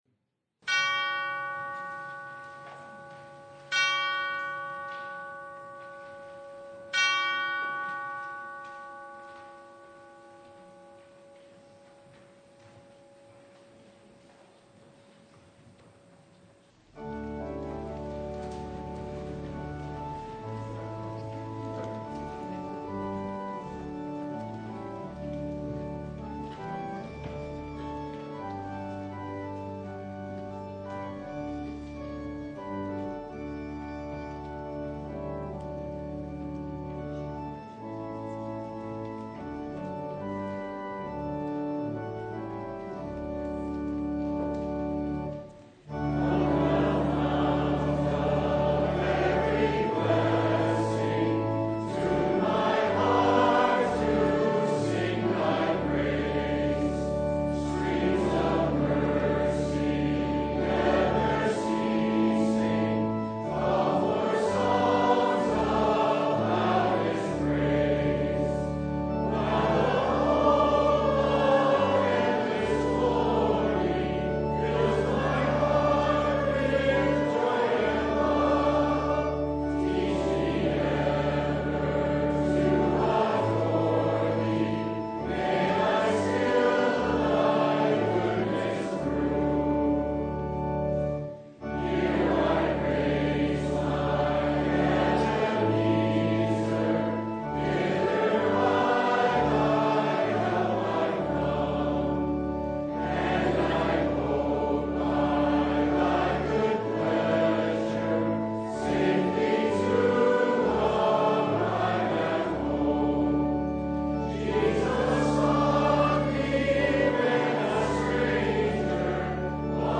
Luke 15:1-10 Service Type: Sunday What the world needs is a Shepherd.